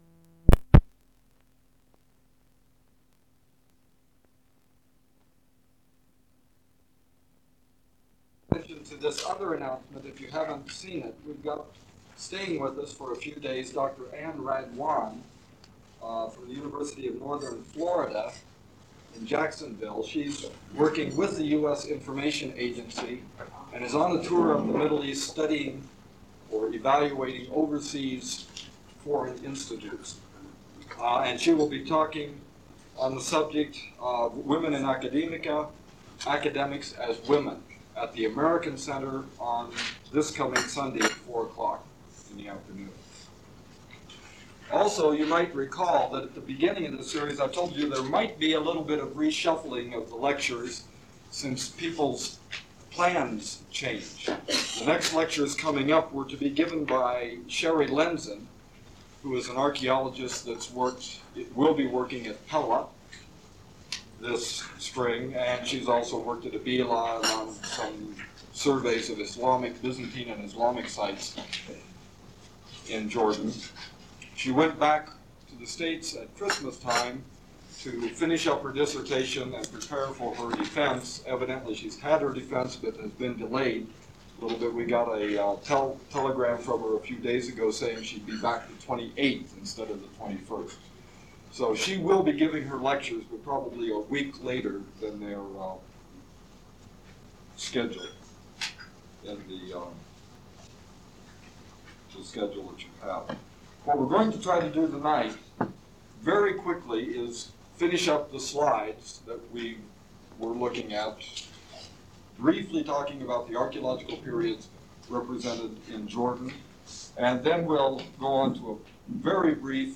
Archaeological Methodology - Lecture 5: Archaeological Periods Represented in Jordan - Brief history of archaeological research, choosing a site to excavate